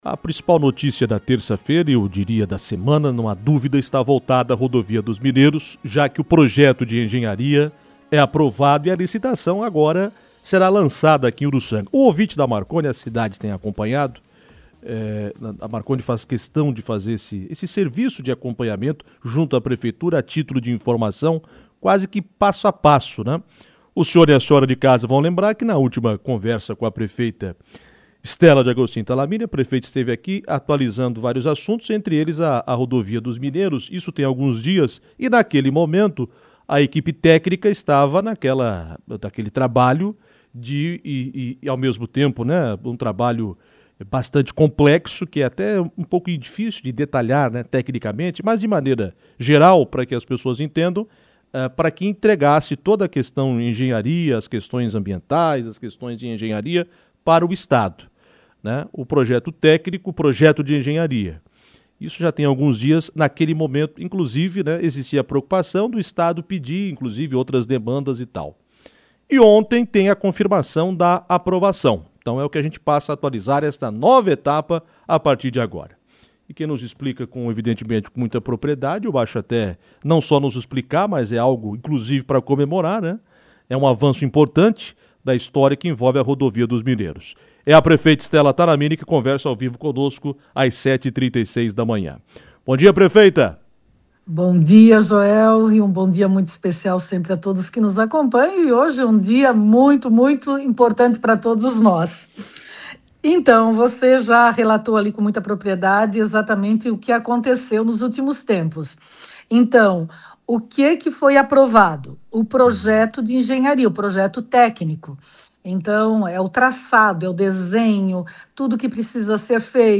Em entrevista, a prefeita Stela Talamini (MDB) relembrou que, desde janeiro, a equipe da pre